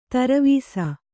♪ taravīsa